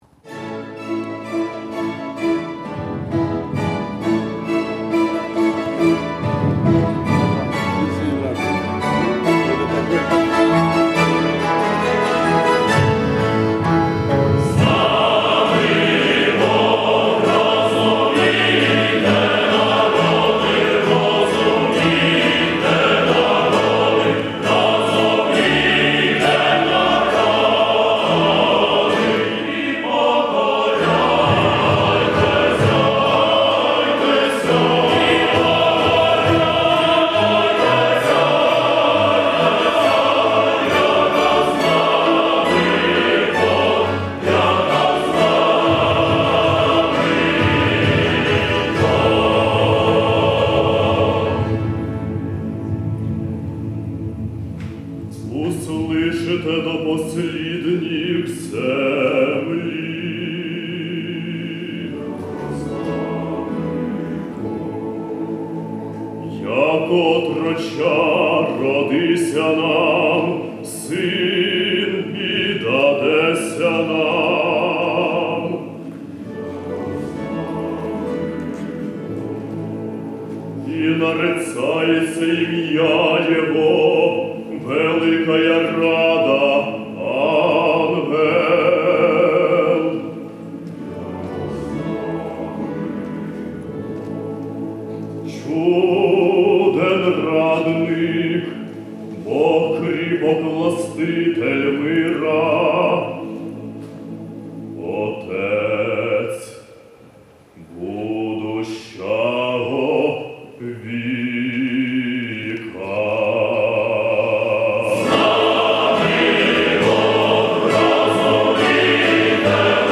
Національна заслужена капела бандуристів України імені Георгія Майбороди — музичний колектив, заснований у Києві 1918 року видатним бандуристом Василем Ємцем. Національна капела бандуристів України — це 45 талановитих чоловіків, які одночасно співають і грають на бандурах.